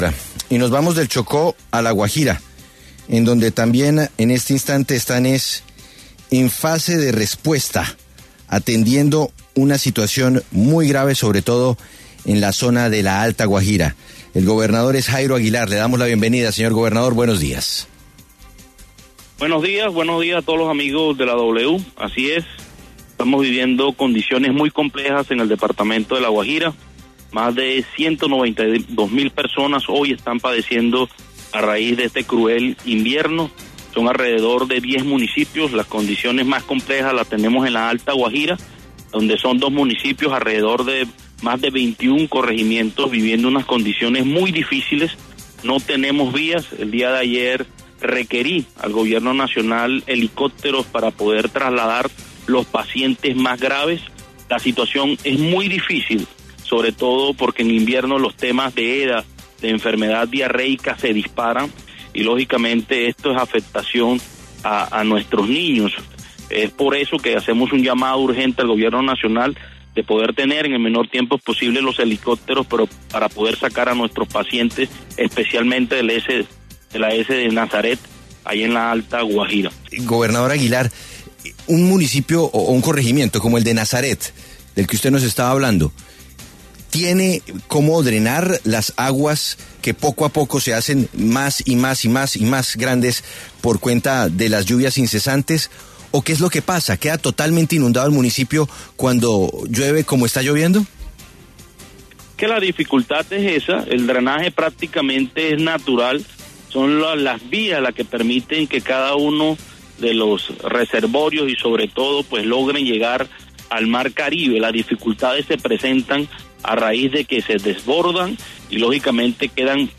Jairo Aguilar, el gobernador de La Guajira, pasó por los micrófonos de La W para hablar sobre cómo vive su departamento esta emergencia.